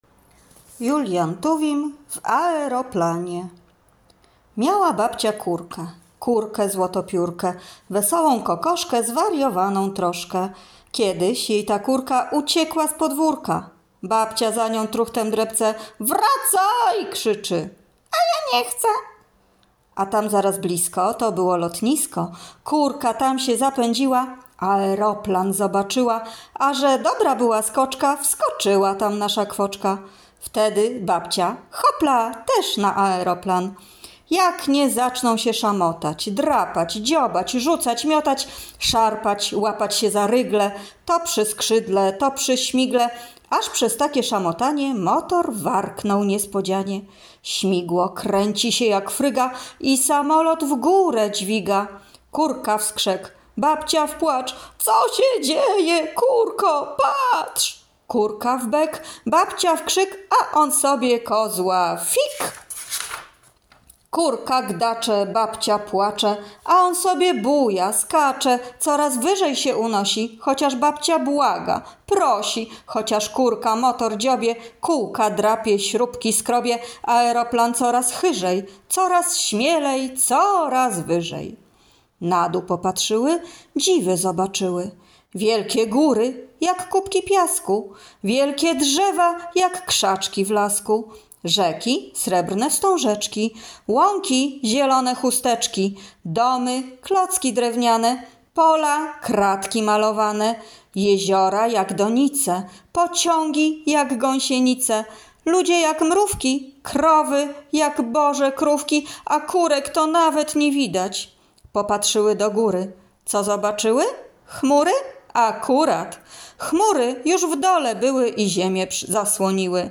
Wiersze